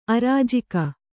♪ arājika